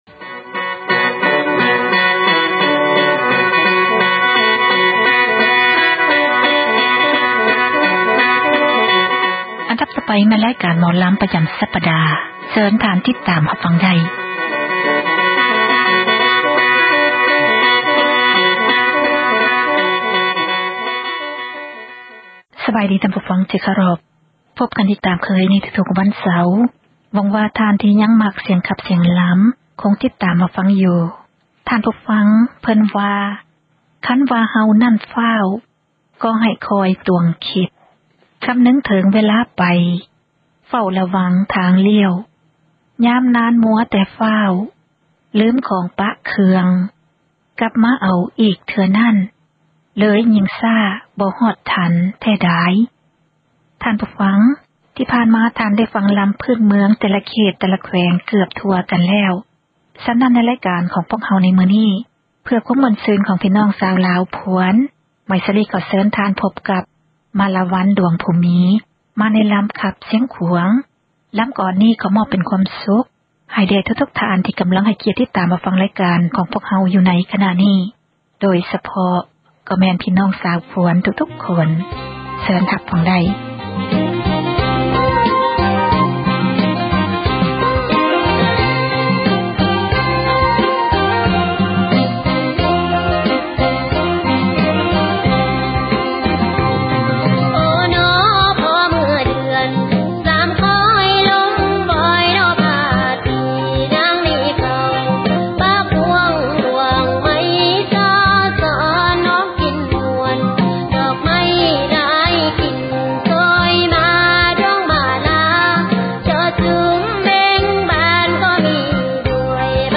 ຣາຍການ ໝໍລໍາລາວ ປະຈໍາສັປດາ ຈັດສເນີທ່ານ ໂດຍ